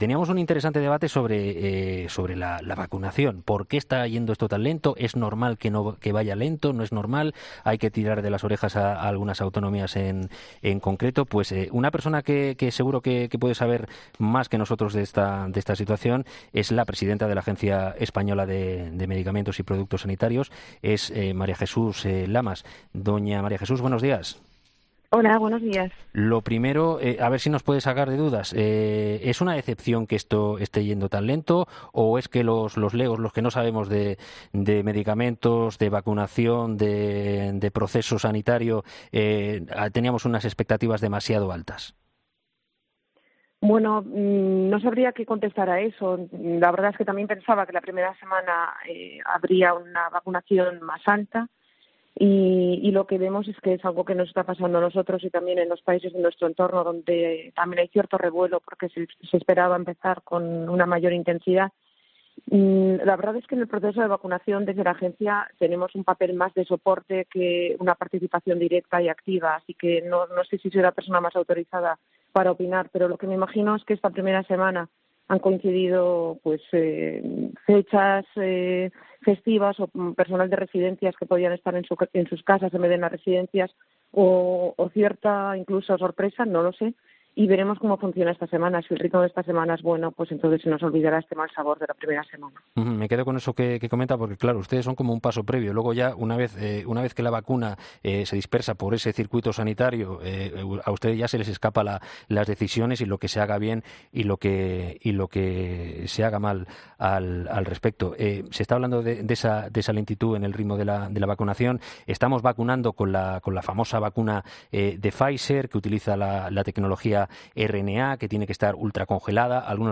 María Jesús Lamas, directora de la Agencia Española de Medicamentos y Productos Sanitarios, ha sido entrevistada este martes en 'Herrera en COPE, donde se ha pronunciado sobre los retrasos en la campaña de vacunación contra la covid.